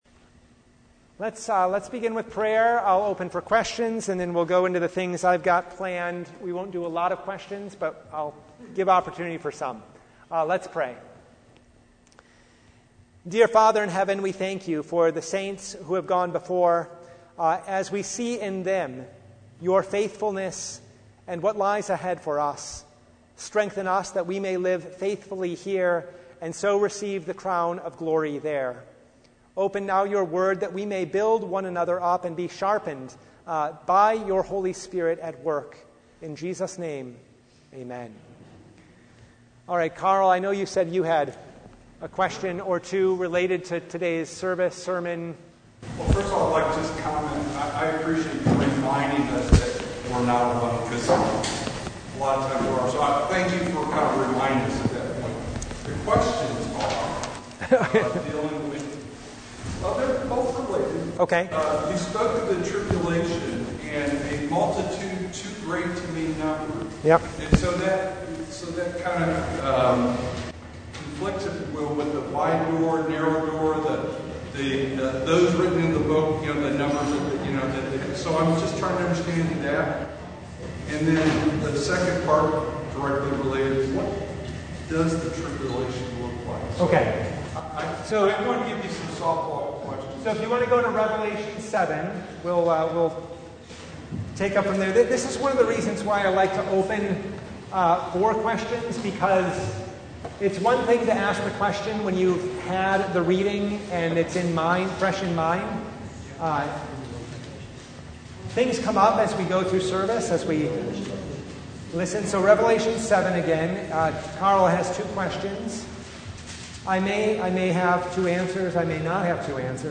A discussion on the tribulation as related to today's scripture in Revelation.